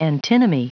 Prononciation du mot antinomy en anglais (fichier audio)
Prononciation du mot : antinomy